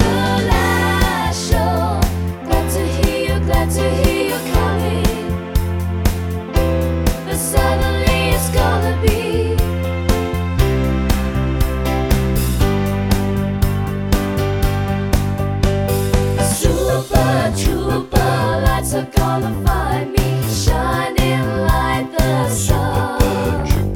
No Guitars Pop (1970s) 4:09 Buy £1.50